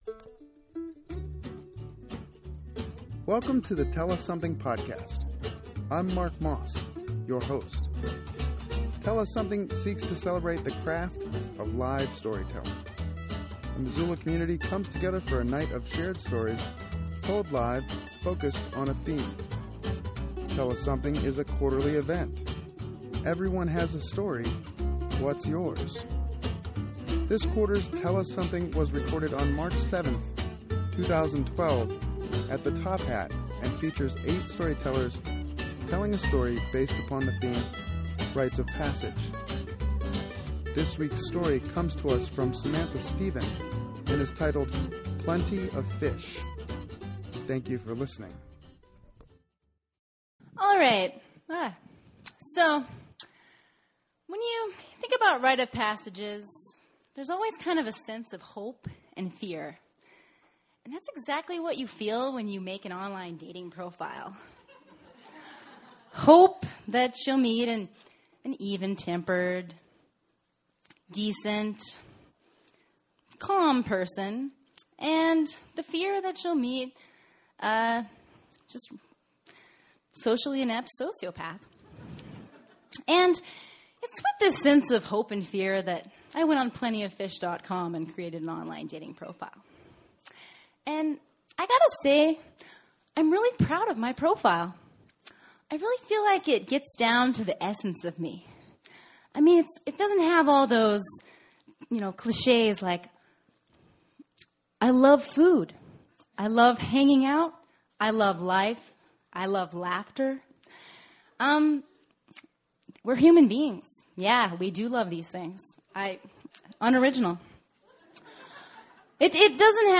This quarter’s Tell us Something was recorded on March 7th, 2012 at the Top Hat. The theme was “Rites of Passage”.